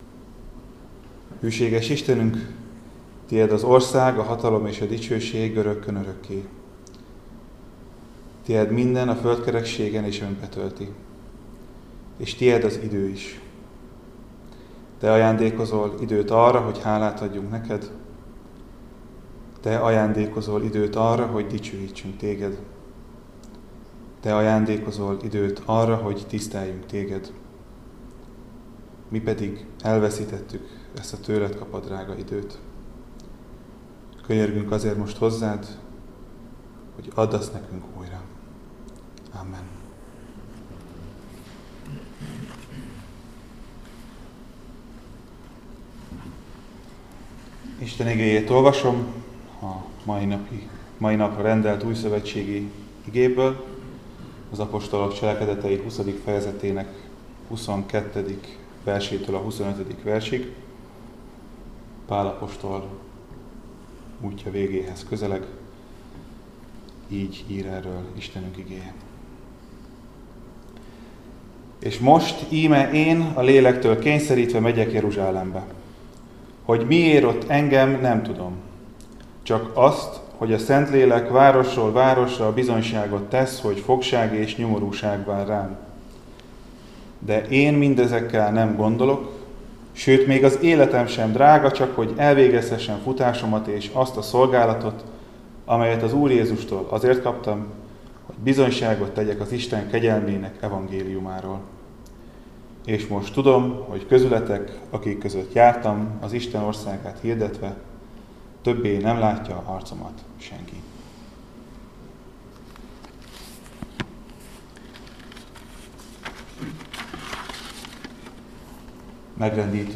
Áhítat, 2025. december 2.